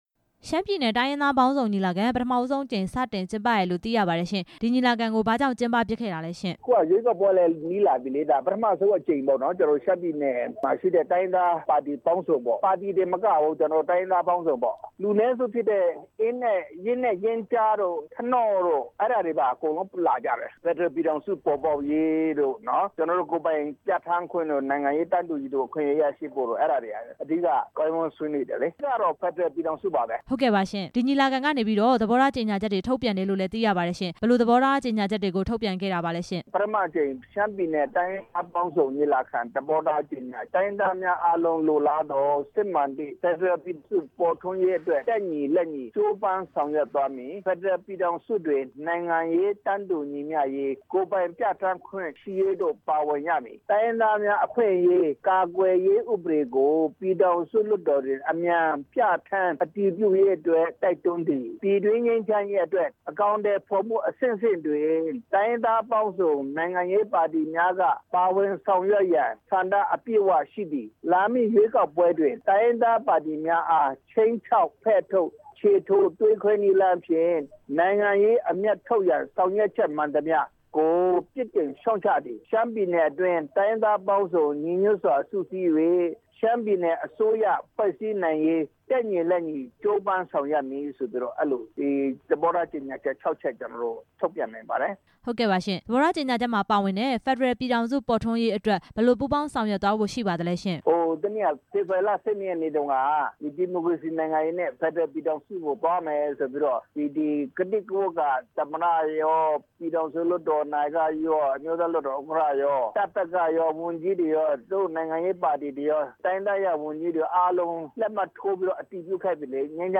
ဦးစိုင်းအိုက်ပေါင်းကို မေးမြန်းချက်